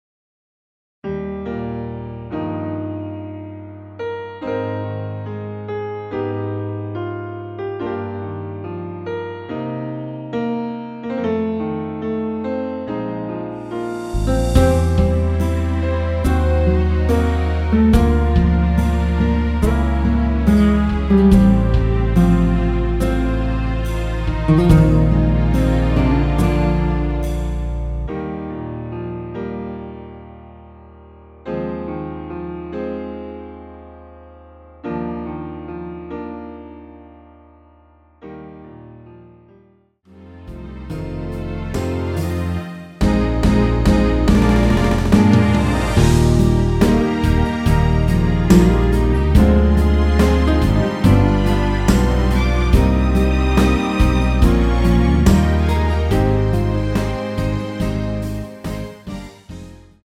Eb
◈ 곡명 옆 (-1)은 반음 내림, (+1)은 반음 올림 입니다.
앞부분30초, 뒷부분30초씩 편집해서 올려 드리고 있습니다.
중간에 음이 끈어지고 다시 나오는 이유는